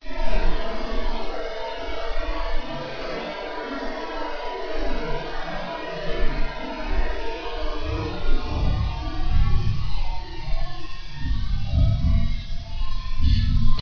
x_ancientsdrone.ogg